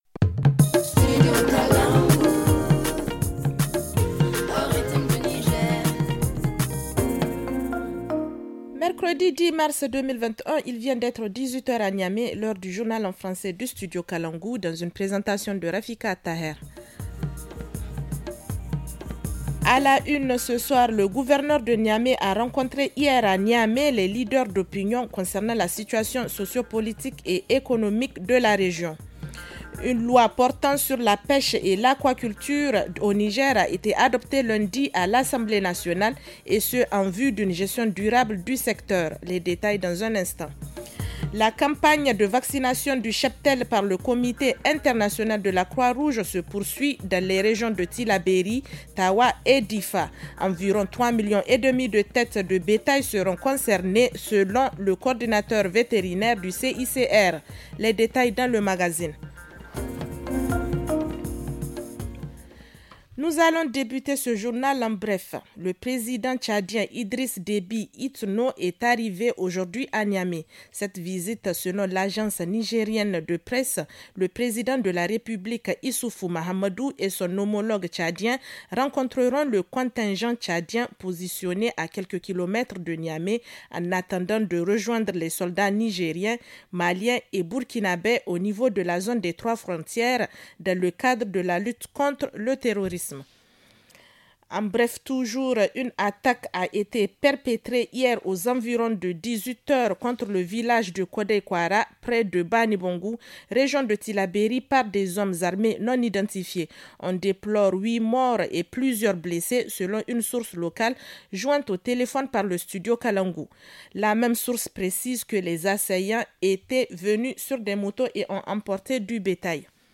Le journal du 10 mars 2021 - Studio Kalangou - Au rythme du Niger